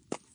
Dirt Foot Step 2.wav